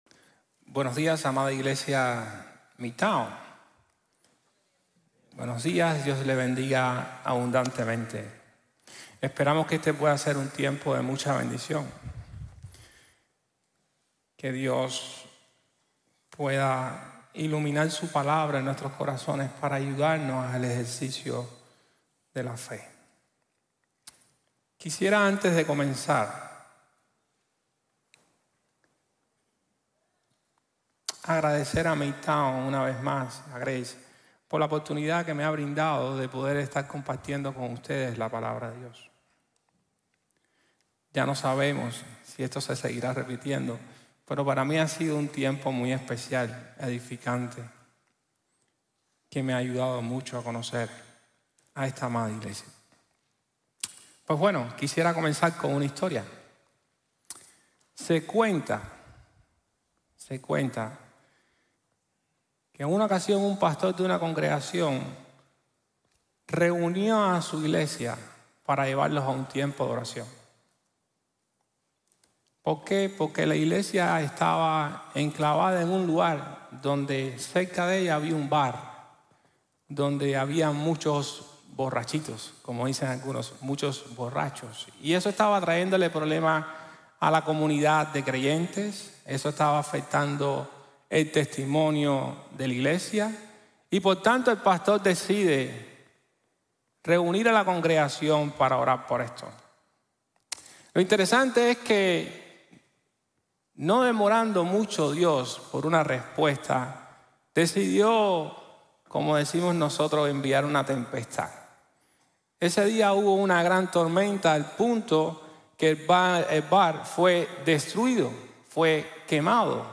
Jesús nos enseña a orar | Sermon | Grace Bible Church